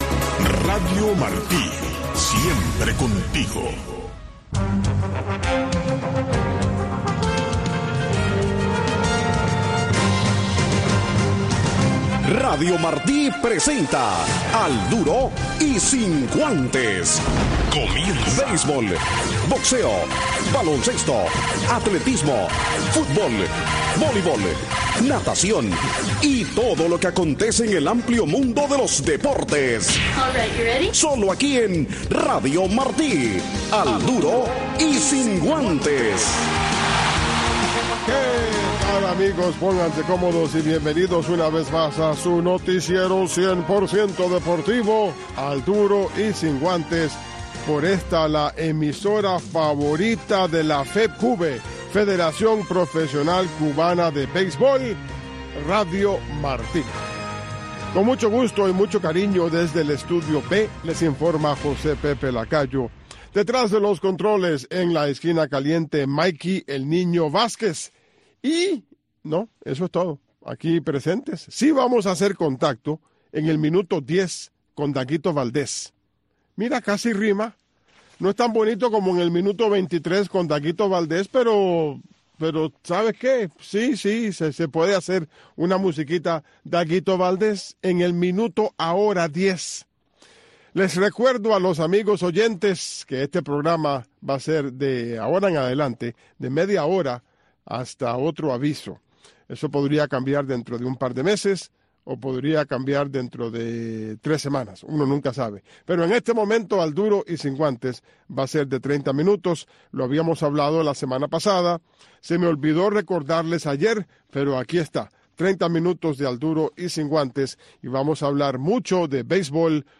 Un resumen deportivo en 60 minutos conducido